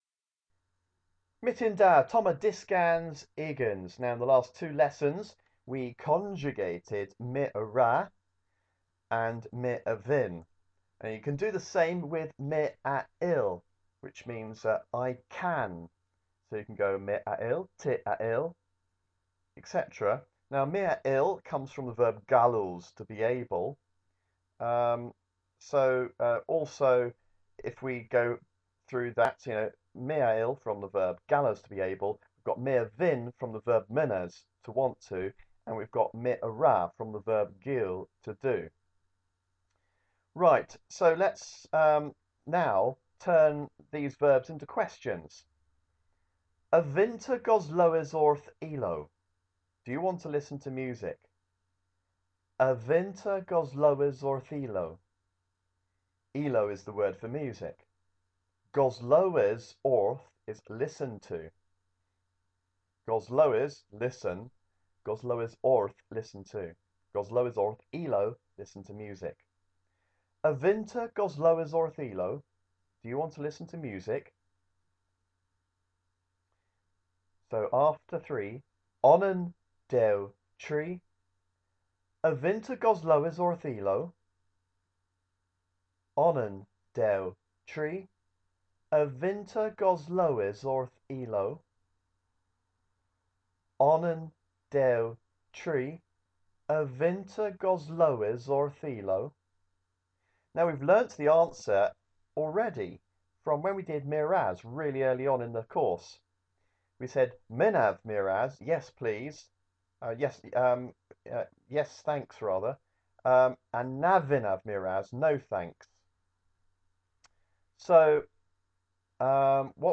Cornish lesson 20 - Dyskans ugens